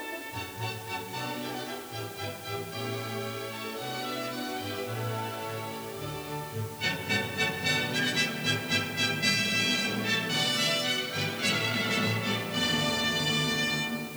These are arranged in degrading quality.
Yet most 8-bit files sound terrible with lots of static & noise.